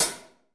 HH 1H.wav